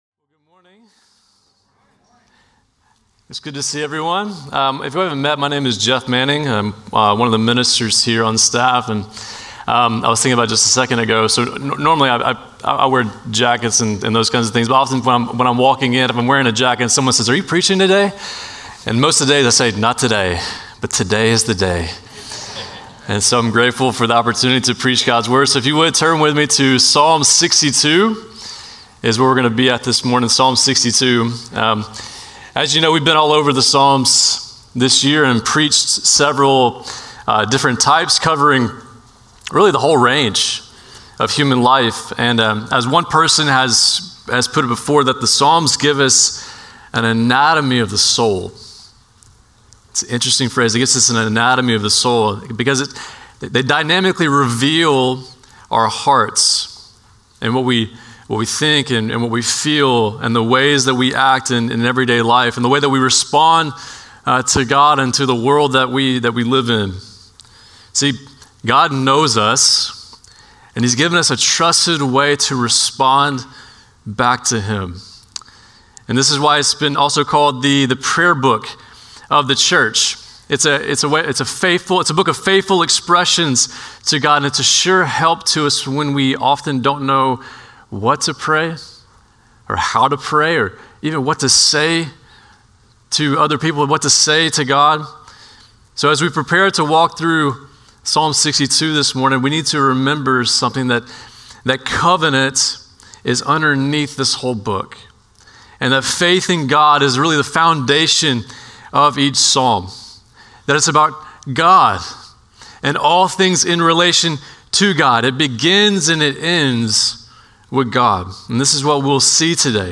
Norris Ferry Sermons Mar. 16, 2025 -- The Book of Psalms -- Psalm 62 Mar 16 2025 | 00:34:24 1x 00:00 / 00:34:24 Subscribe Share Spotify RSS Feed Share Link Embed